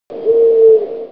hoot.mp3